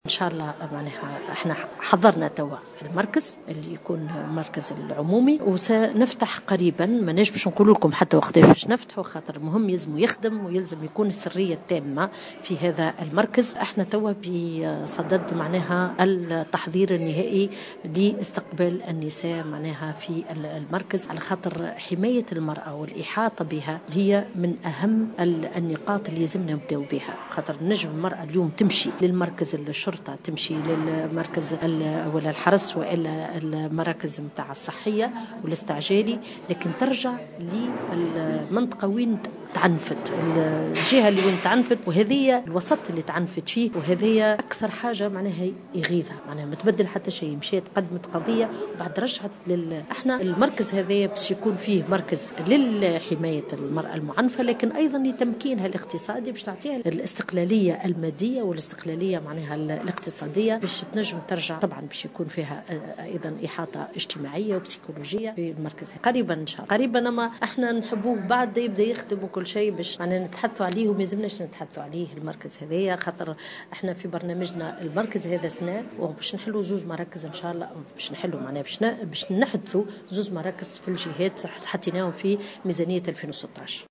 أكدت وزيرة المرأة سميرة مرعي خلال ندوة عقدت اليوم الخميس 20 أوت 2015 حول "العنف السياسي" أنه تم إحداث مركز عمومي لحماية المرأة المعنفة وأنه سيفتتح قريبا.